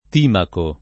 Timaco [ t & mako ]